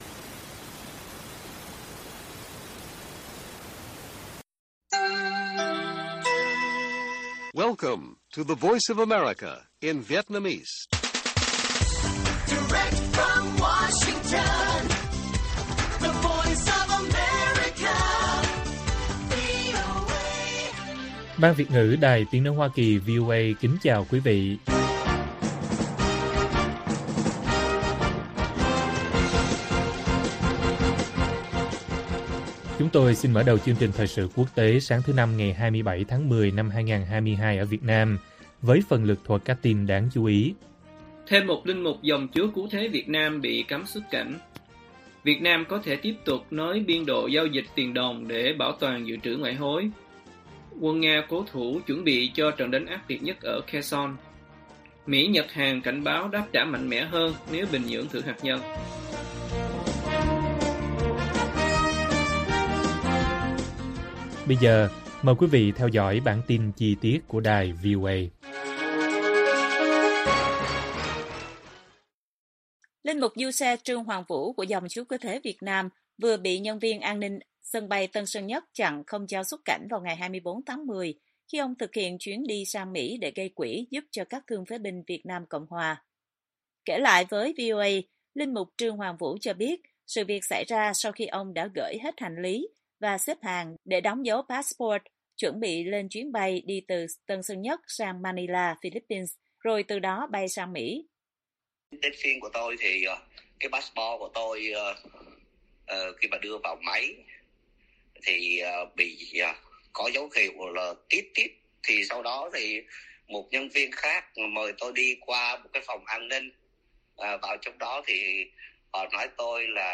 Quân Nga cố thủ, chuẩn bị cho trận đánh ác liệt nhất ở Kherson - Bản tin VOA